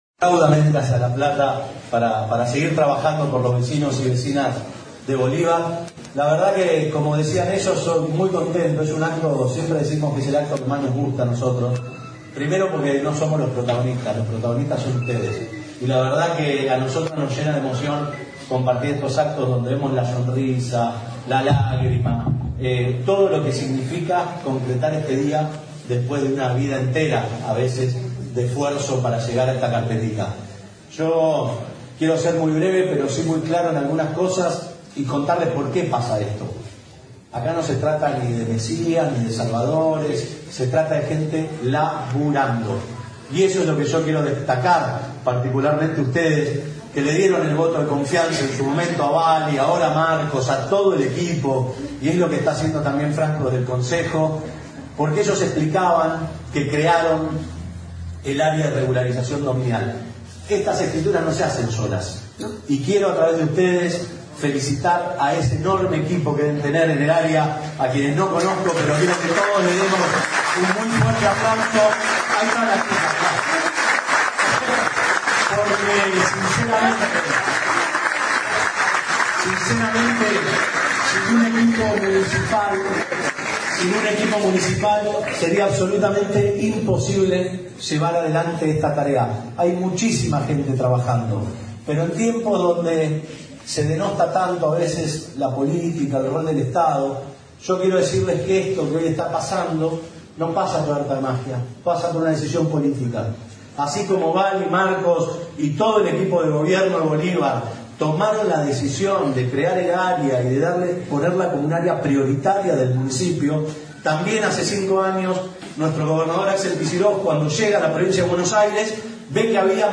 ACTO DE ENTREGA DE ESCRITURAS
AUDIO - Juan Mena Ministro de Justicia y Derechos Humanos